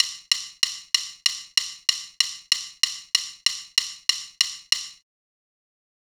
klick-stick-wav.30172